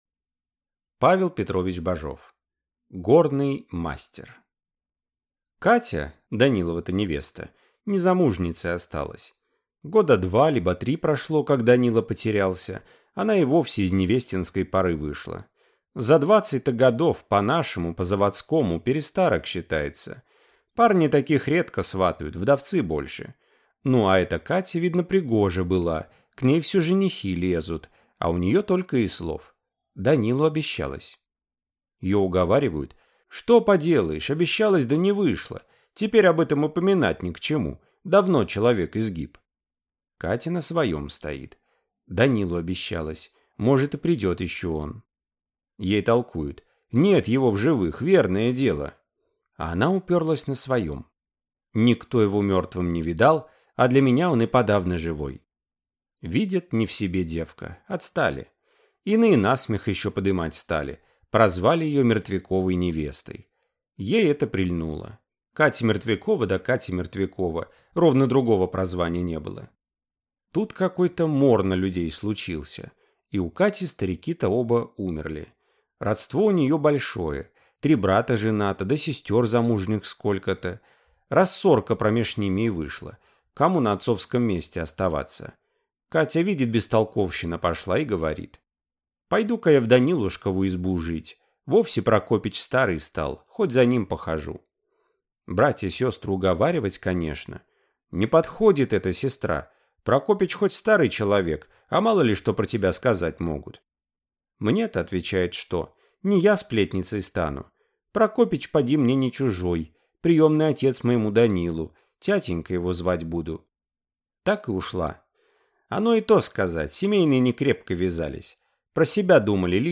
Аудиокнига Горный мастер | Библиотека аудиокниг